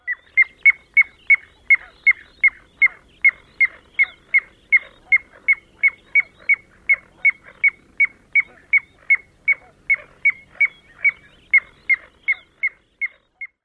笛鸻鸟叫声 示警声